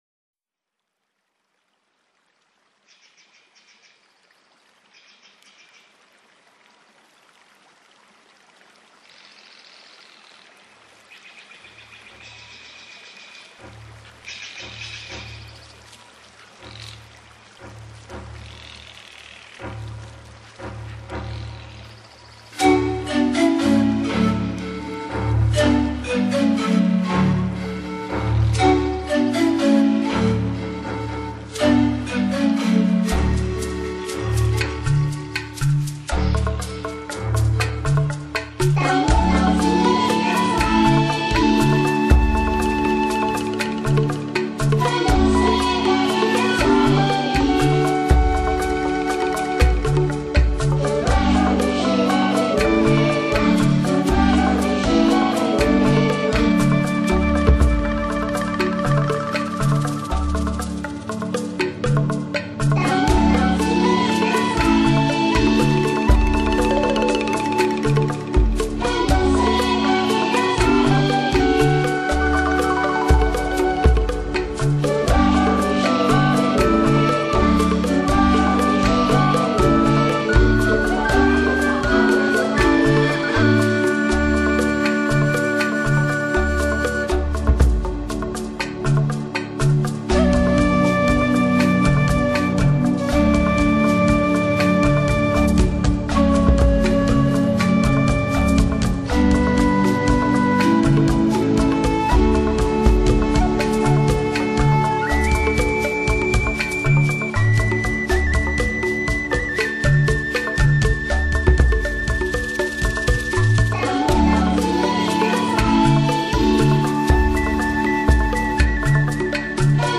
音乐类型: Newage
月亮当空，偶尔响起的快板，像顽皮的孩子，在月夜下嬉戏。低沉的音乐安抚着大地，
鸟儿鸣唱，婉转动听，仿佛诉说着白天的故事。